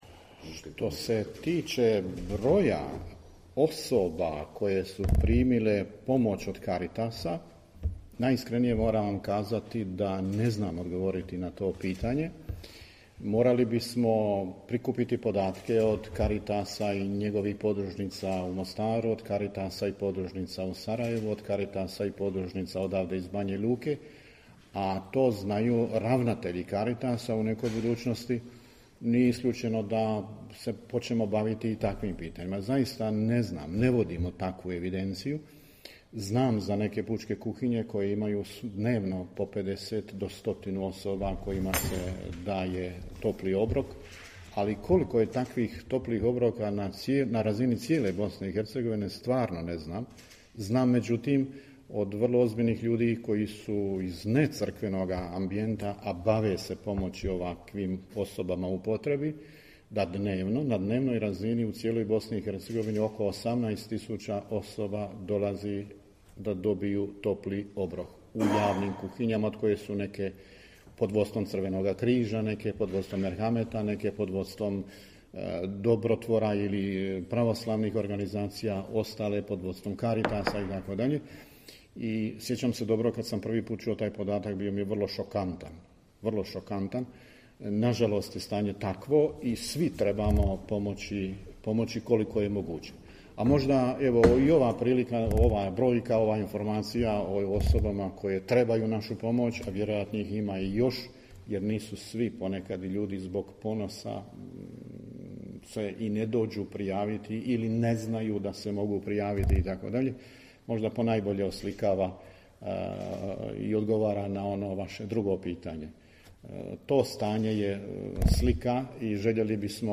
Audio: Nadbiskup Vukšić i biskup Majić na konferenciji za novinstvo govorili o 90. redovitom zasjedanju BK BiH